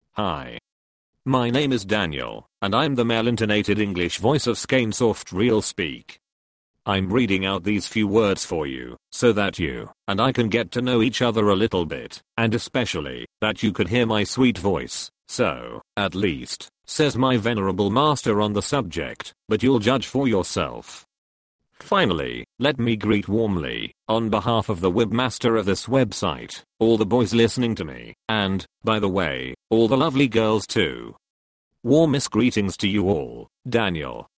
Texte de démonstration lu par Daniel, voix masculine anglaise (Nuance RealSpeak; distribué sur le site de Nextup Technology; homme; anglais)